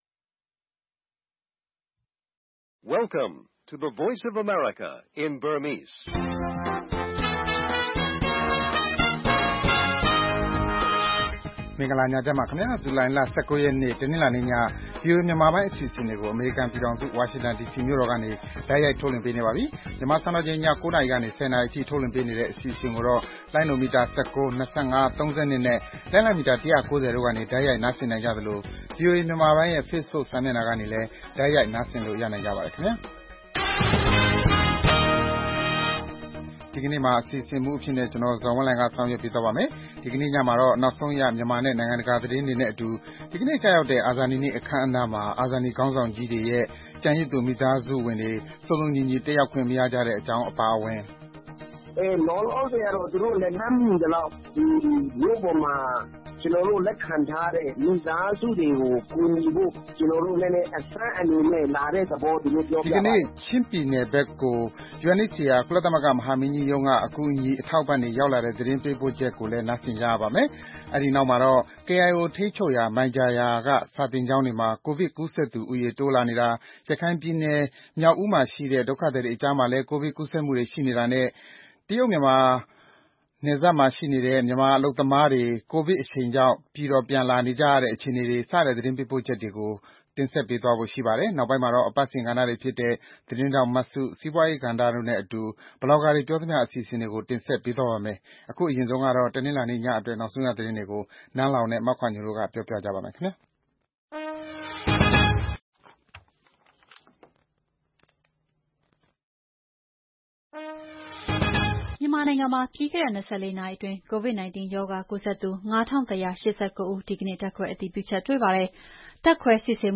VOA ရေဒီယိုညပိုင်း ၉း၀၀-၁၀း၀၀ တိုက်ရိုက်ထုတ်လွှင့်မှု